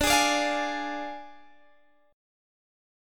Ddim Chord
Listen to Ddim strummed